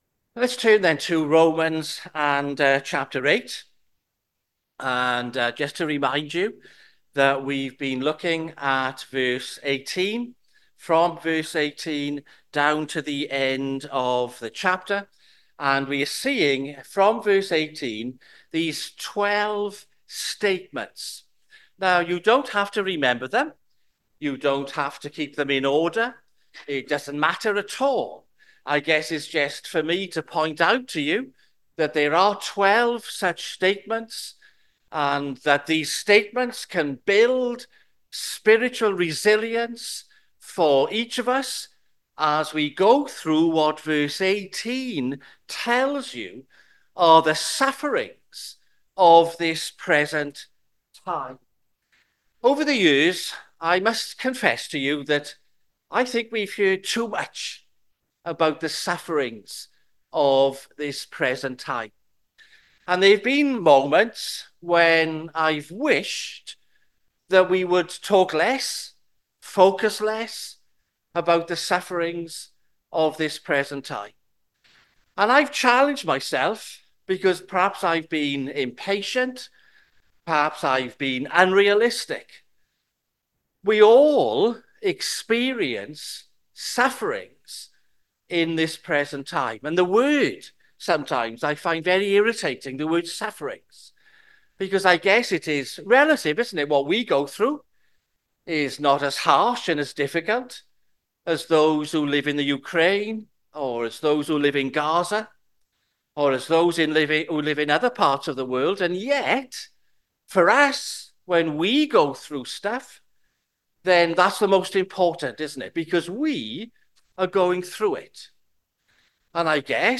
Sermons Tabernacle Church - 1st December 2024 - Who shall condemn the Lord's elect Play Episode Pause Episode Mute/Unmute Episode Rewind 10 Seconds 1x Fast Forward 30 seconds 00:00 / 34:58 Subscribe Share RSS Feed Share Link Embed
sermon-2024-l-1st-December-am.mp3